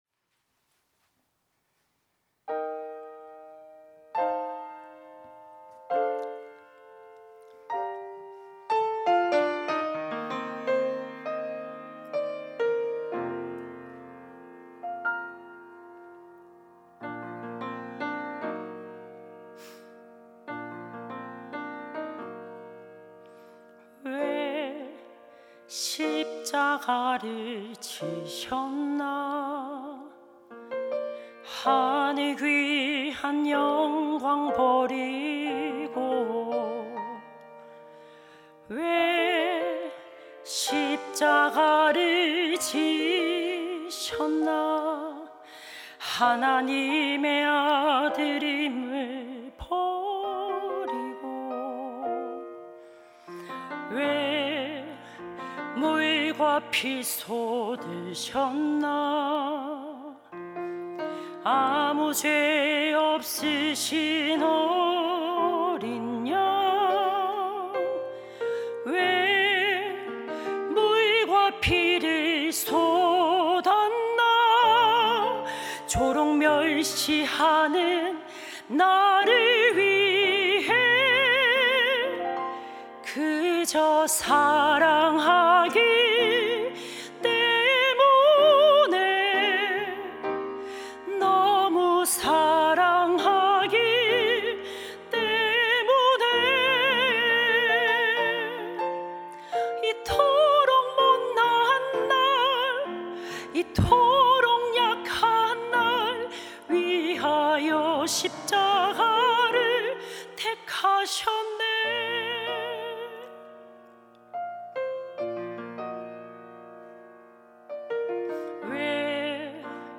특송과 특주 - 그저 사랑하기 때문에